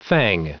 Prononciation du mot fang en anglais (fichier audio)
Prononciation du mot : fang